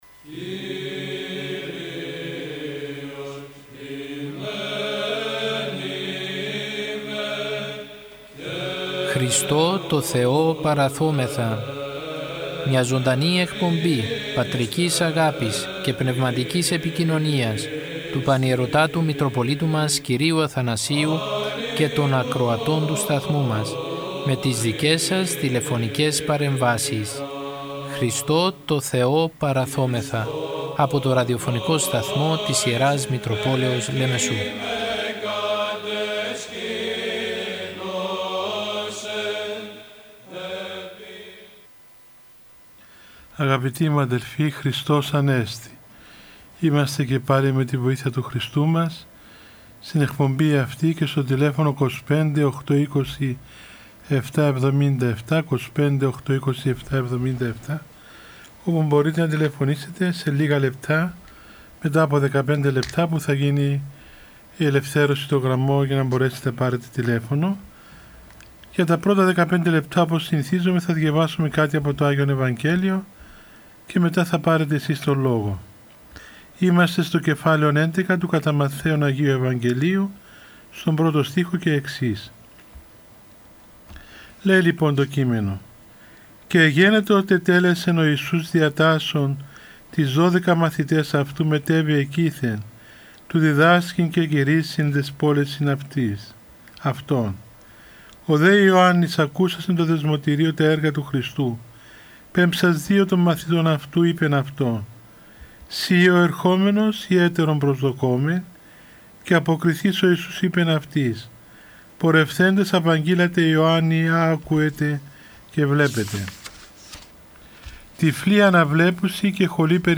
Η Ιερά Μητρόπολη Λεμεσού μετά από το διάταγμα που εξέδωσε η Κυβέρνηση για την αντιμετώπιση της πανδημίας του κορωνοϊού που περιορίζει τις μετακινήσεις και την προσέλευση των πιστών στους ναούς, θέλοντας να οικοδομήσει και να στηρίξει ψυχικά και πνευματικά όλους τους πιστούς παρουσιάζει καθημερινά ζωντανές εκπομπές με τον Πανιερώτατο Μητροπολίτη Λεμεσού κ. Αθανάσιο, με τίτλο...
Ο Πανιερώτατος πραγματοποιεί απαντά στις τηλεφωνικές παρεμβάσεις των ακροατών του Ραδιοφωνικού Σταθμού της Ι. Μ. Λεμεσού και απευθύνει λόγο παρηγορητικό, παραμυθητικό και ποιμαντικό.